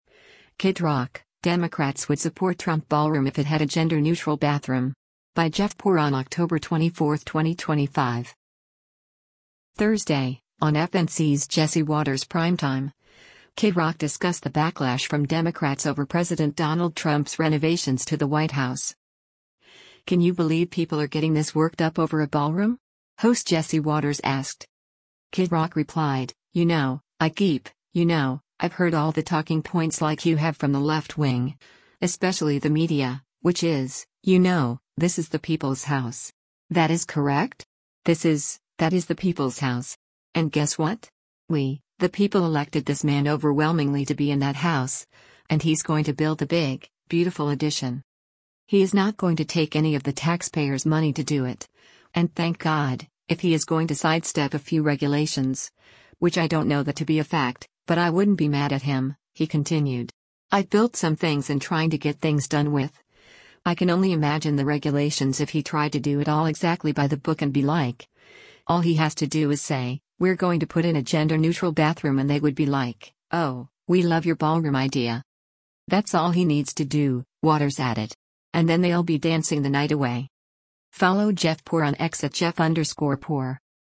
Thursday, on FNC’s “Jesse Watters Primetime,” Kid Rock discussed the backlash from Democrats over President Donald Trump’s renovations to the White House.